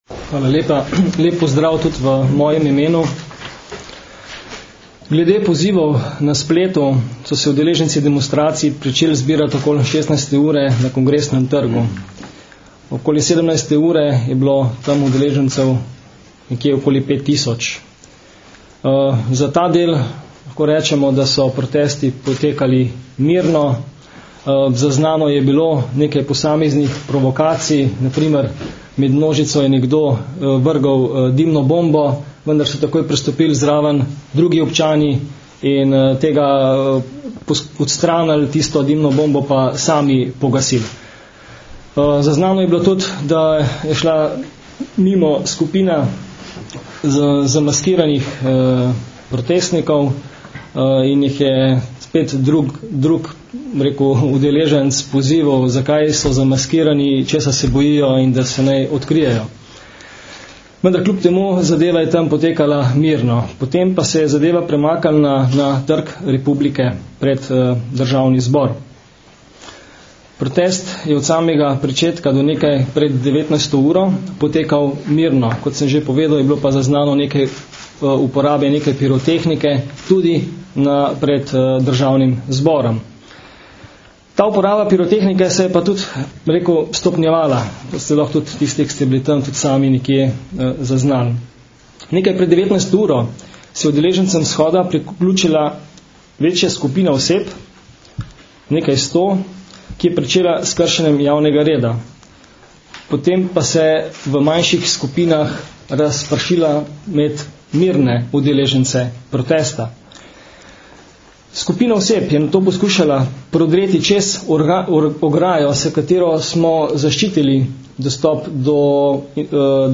Podrobnosti včerajšnjih dogodkov in policijskih ukrepov sta na današnji novinarski konferenci predstavila generalni direktor policije Stanislav Veniger in direktor Policijske uprave Ljubljana mag. Stanislav Vrečar.
Zvočni posnetek izjave mag. Stanislava Vrečarja (mp3)